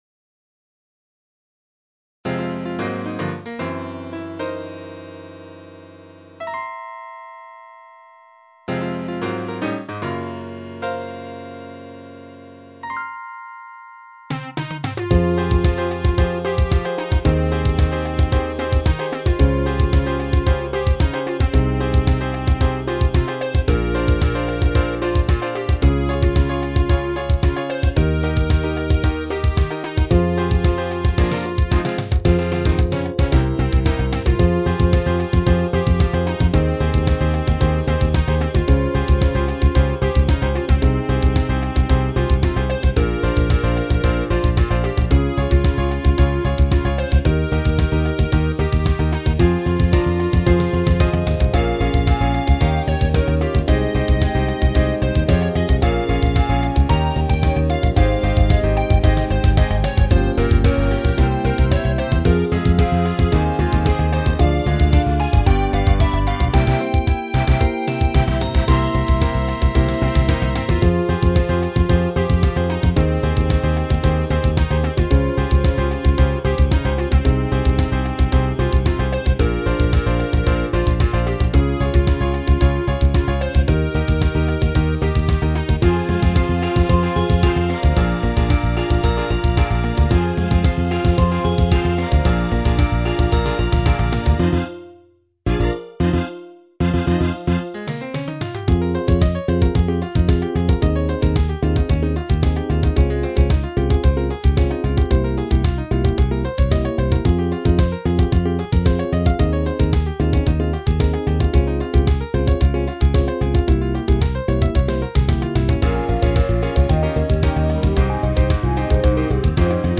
テンポを少し落としてラテン＆クラシック調。かつ短く簡潔に。